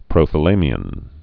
(prōthə-lāmē-ən, -ŏn)